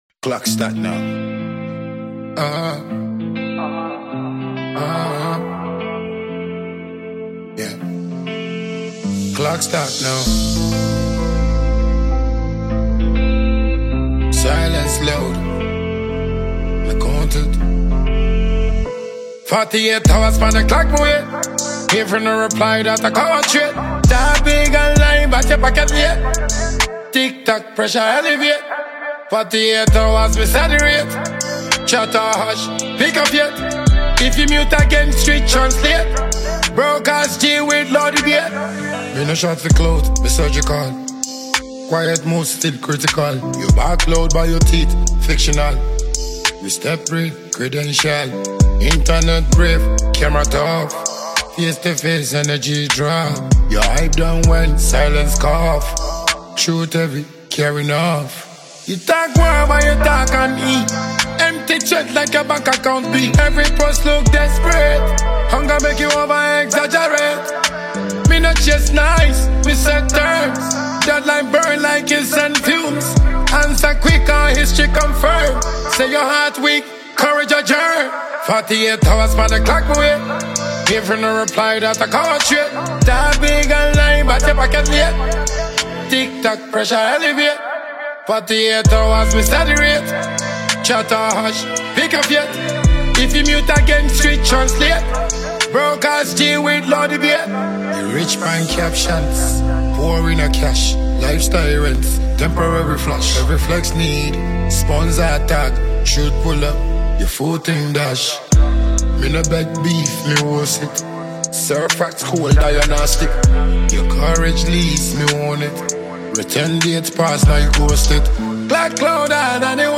Ghanaian dancehall and afrobeat musician